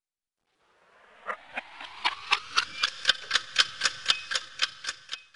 clockwithdoppler1.wav